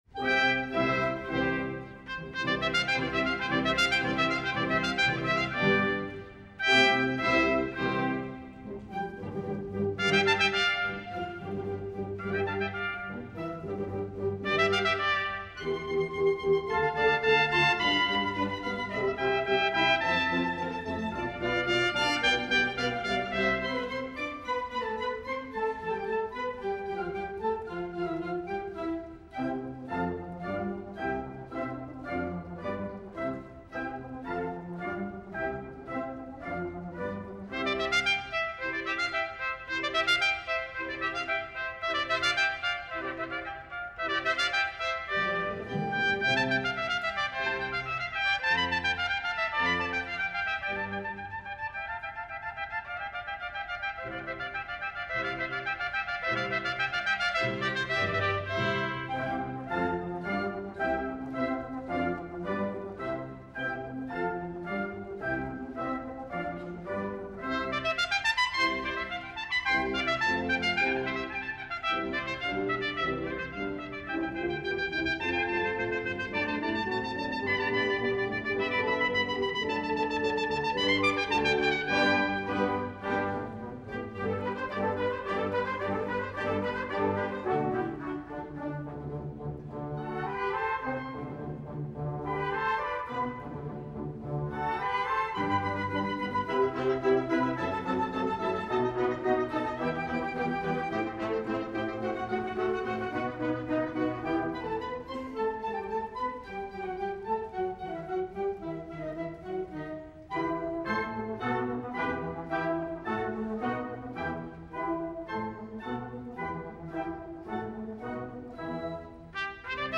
Voicing: Trumpet Duet w/ Band